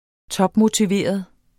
Udtale [ -motiˌveˀʌð ]